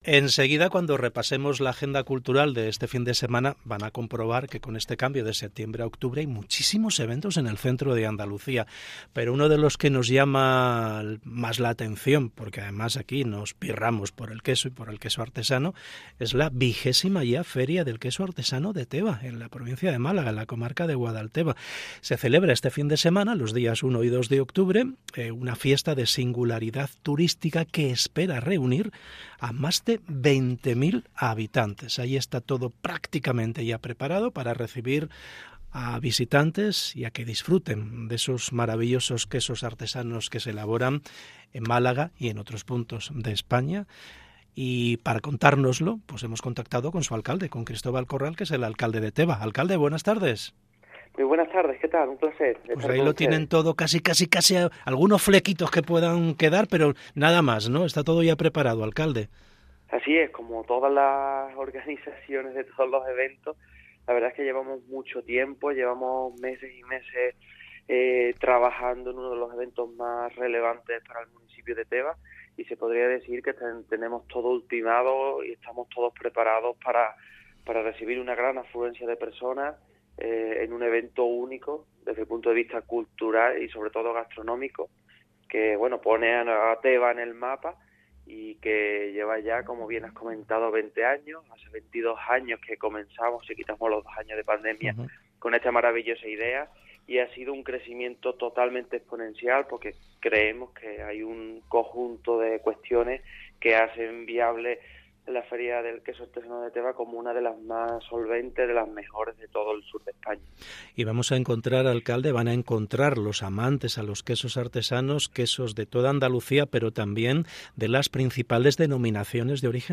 ENTREVISTA | Cristóbal Corral (Alcalde Teba) 30 septiembre 2022